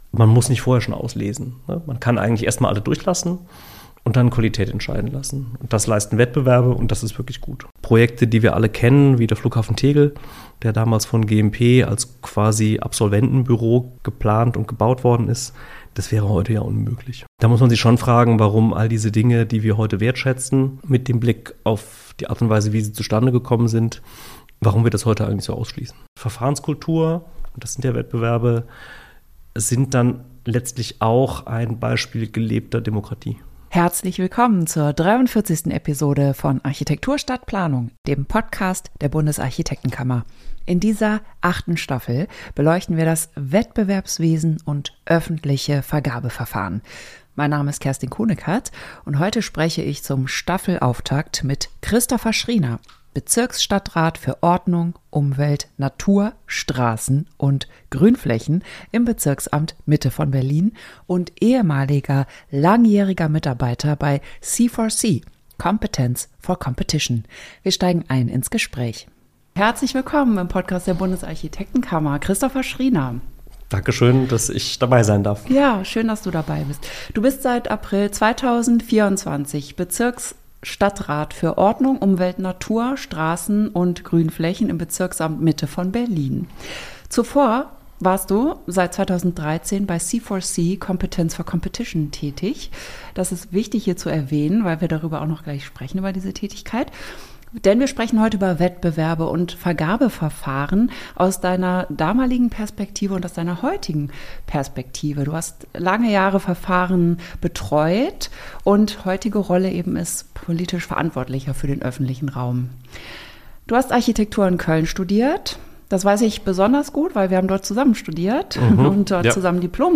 Er erklärt, warum der offene Wettbewerb nicht für jede Aufgabe die richtige Wahl ist und warum das Verfahren trotzdem unterschätzt wird: als Schutz vor individueller Präferenz, als Zeitgewinn für Bauherren und als Chance, Öffentlichkeit einzubinden. Ein Gespräch über Vertrauen, Vergaberecht und Verfahrenskultur als Teil gelebter Demokratie.